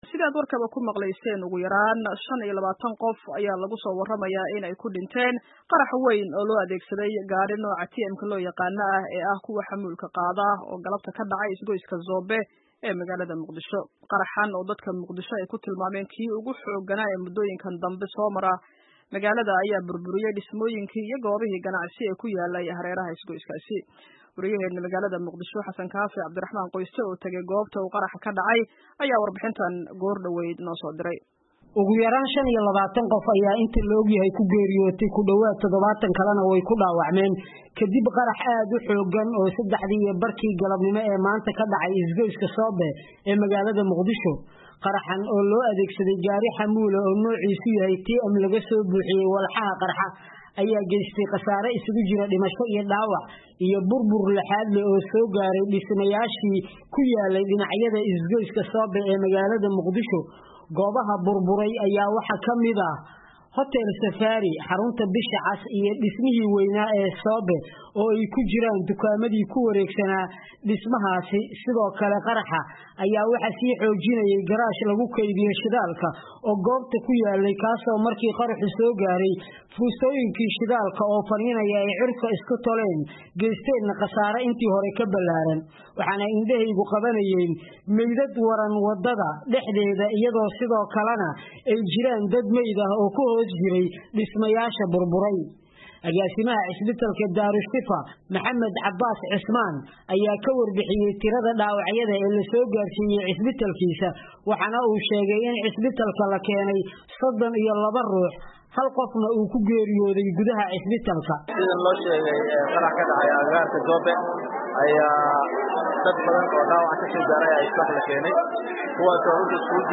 Dhagayso warbixin dheer oo ay VOA ka diyaarisay caawa khasaaraha qaraxa